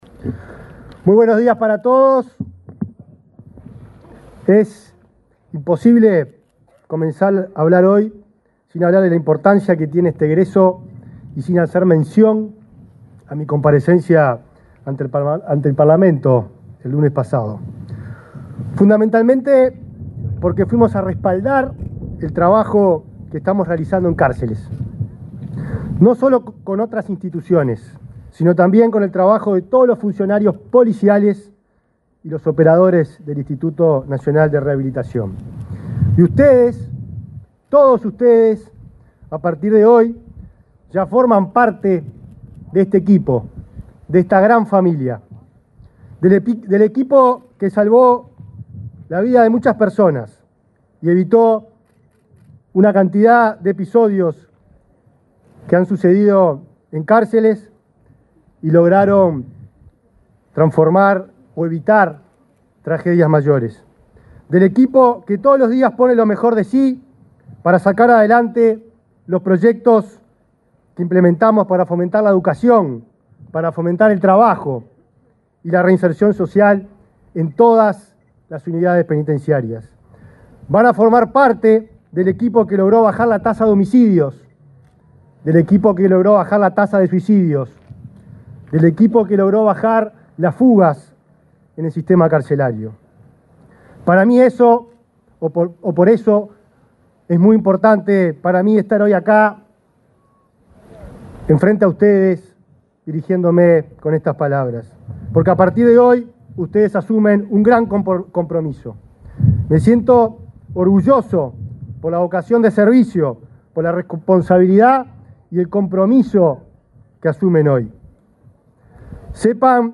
Palabras del ministro del Interior, Nicolás Martinelli
Palabras del ministro del Interior, Nicolás Martinelli 09/10/2024 Compartir Facebook X Copiar enlace WhatsApp LinkedIn El ministro del Interior, Nicolás Martinelli, se expresó durante la ceremonia de egreso de agentes penitenciarios, realizada en la intersección de los caminos Basilio Muñoz y Flores, en Montevideo.